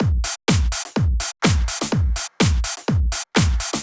32 Drumloop.wav